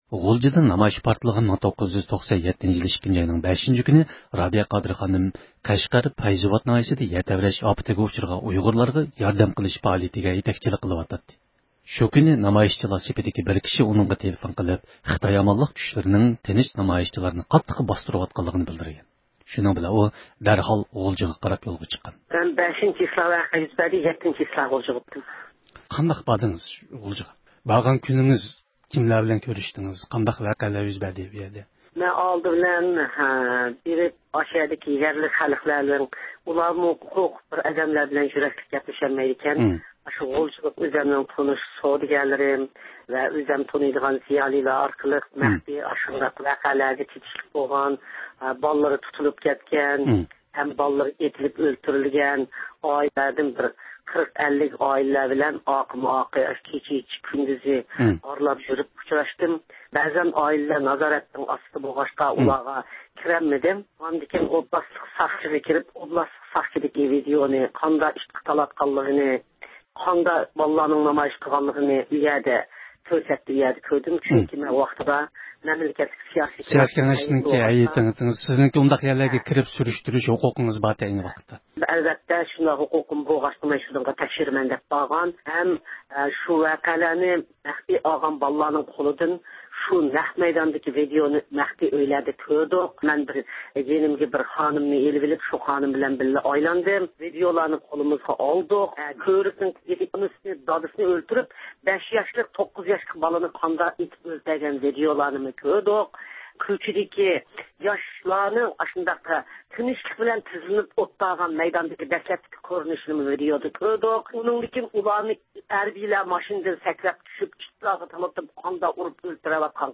رابىيە قادىر خانىم مەزكۇر ۋەقەنىڭ 16 يىللىق خاتىرە كۈنى مۇناسىۋىتى بىلەن زىيارىتىمىزنى قوبۇل قىلىپ، ئۆزىنىڭ غۇلجىدىكى تەكشۈرۈشى، خىتاي يەرلىك ۋە مەركىزى ھۆكۈمىتىنىڭ پوزىتسىيىسى قاتارلىق مەسىلىلەر ھەققىدە توختالدى.